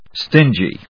音節stin・gy 発音記号・読み方
/stíndʒi(米国英語), ˈstɪndʒi:(英国英語)/